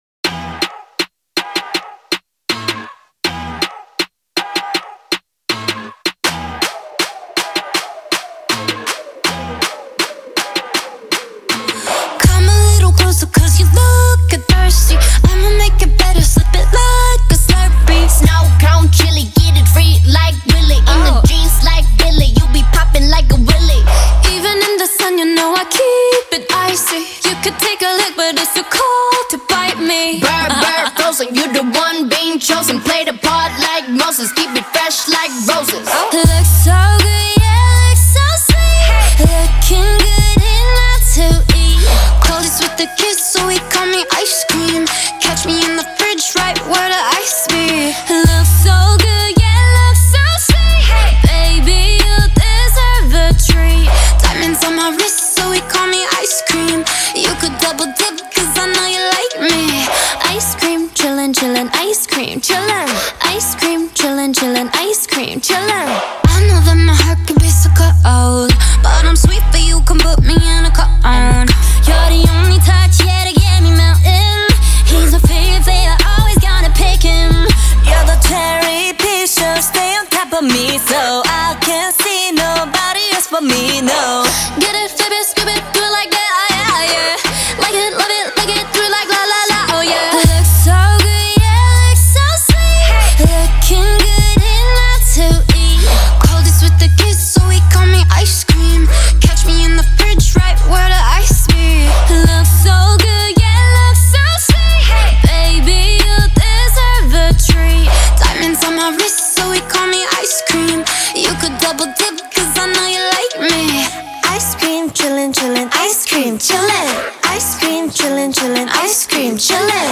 BPM80-80
Audio QualityPerfect (High Quality)
K-Pop song for StepMania, ITGmania, Project Outfox
Full Length Song (not arcade length cut)